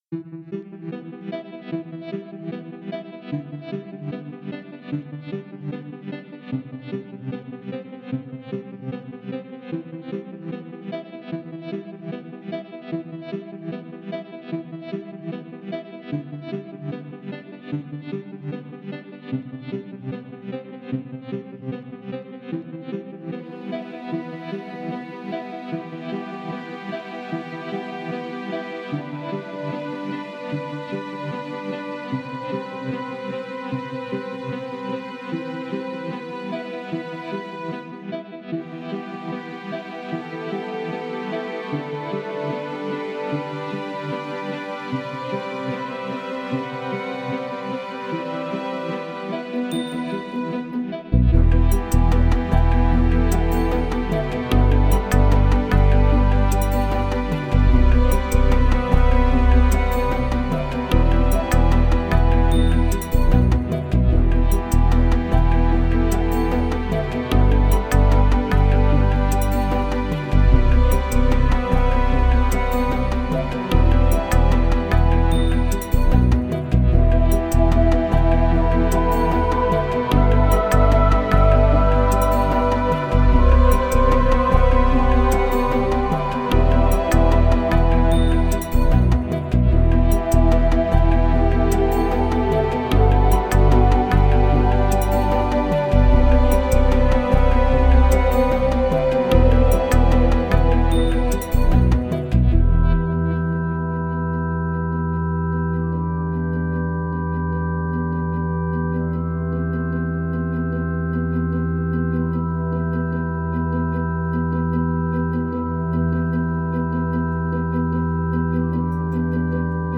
Category: Ambient